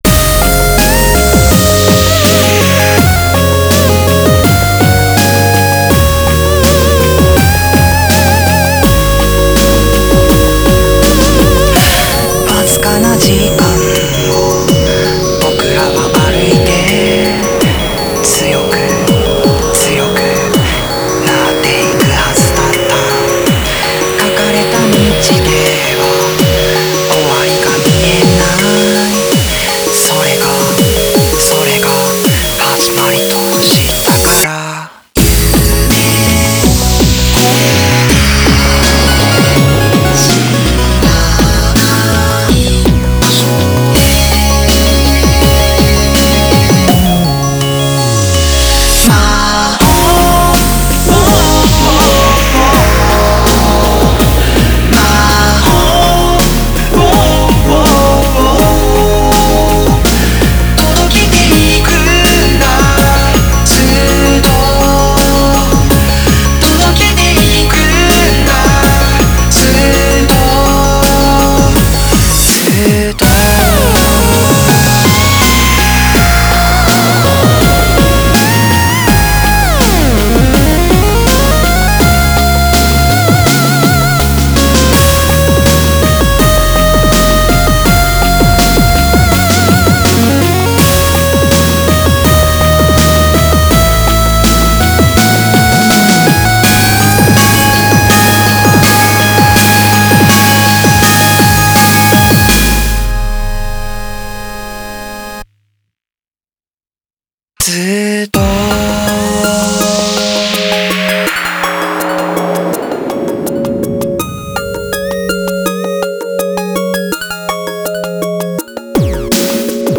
BPM10-82
Audio QualityMusic Cut